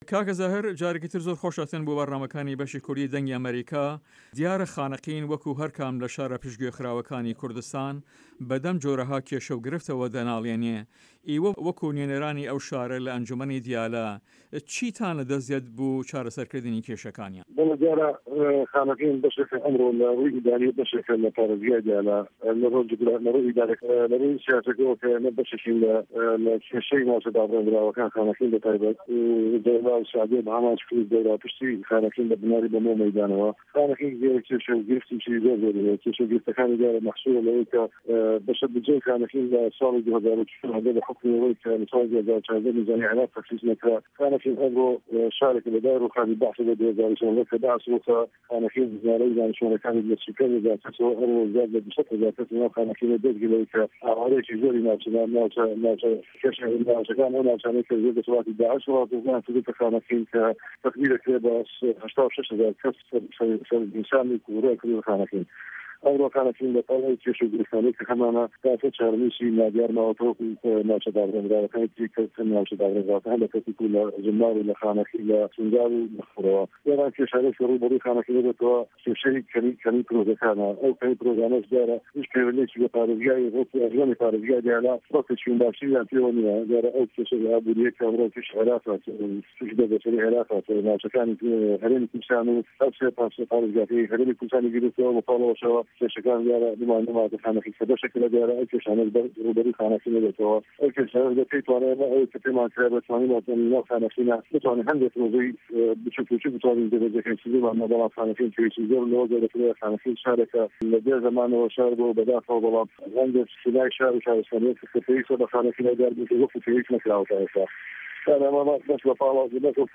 زاهد تاهیر خه‌لیل ئه‌ندام ئه‌نجومه‌نی ئوستان وه‌ یاخود پاریزگاری دیالی له‌ هه‌ڤپه‌یڤینێکدا له‌گه‌ڵ به‌شی کوردی ده‌نگی ئه‌مه‌ریکا ده‌ڵێت" خانه‌قین کێشه‌ و گرفتێکی زۆری هه‌یه‌ و له‌ پاڵ ئه‌وه‌دا هێشتا چاره‌نووسی نادایار ماوه‌ته‌وه‌ وه‌کو به‌شێک له‌ ناوچه‌ دابراوه‌کان و ئه‌و کێشانه‌ی روبه‌رووی خانه‌قین بوه‌ته‌وه‌ که‌می پرۆژه‌کانه‌ که‌ په‌یوه‌ندی به‌و گرفته‌ ئابوریه‌ی که‌ ئه‌مرۆ له‌ عێراق و هه‌رێمی کوردستاندا هه‌یه.